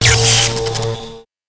combined RA2 tesla tank shot with RA1 tesla sounds for presence and thematic cohesion